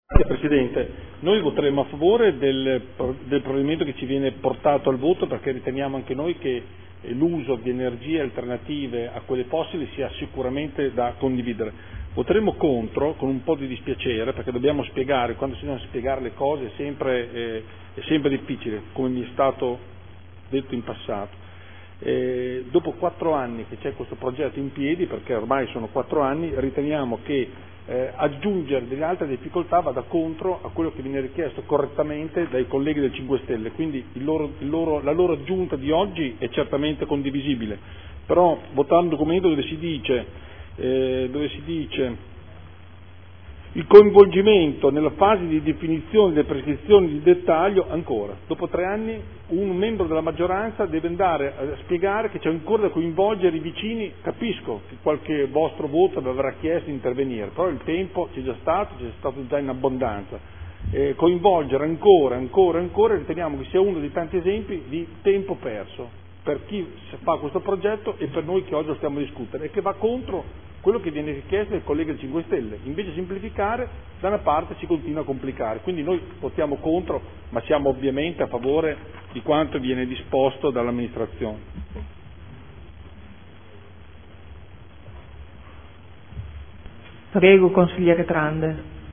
Seduta del 09/03/2015 Progetto di impianto idroelettrico sul Fiume Panaro, Località San Donnino, Comune di Modena, proponente Società DGM Srl – Espressione in merito alla valutazione di impatto ambientale (VIA), alla variante al vigente Piano Regolatore Comunale (POC) ed all’opposizione del vincolo preordinato all’esproprio – Dibattito sulla mozione n°30746